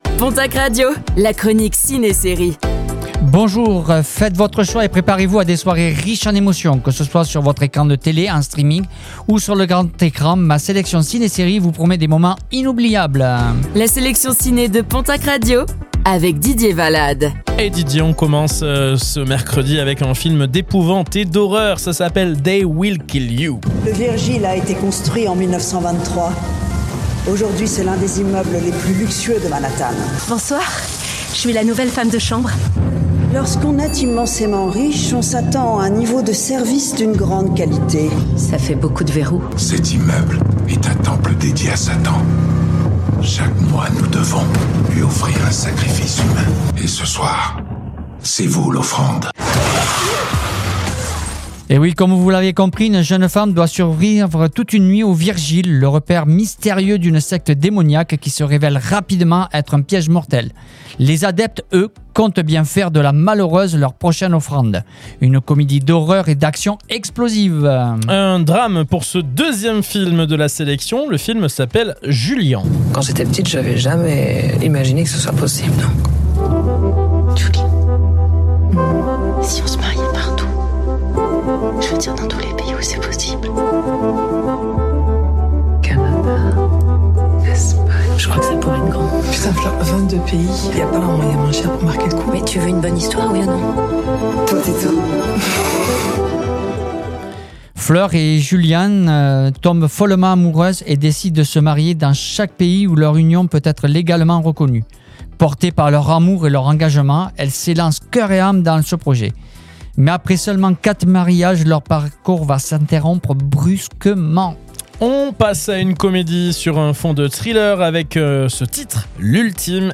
Chronique ciné-séries - Mercredi 25 mars 2026